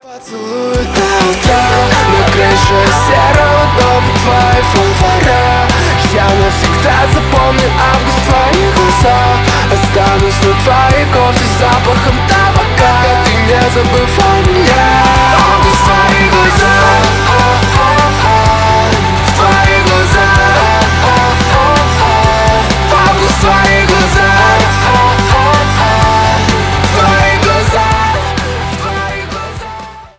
• Качество: 320, Stereo
гитара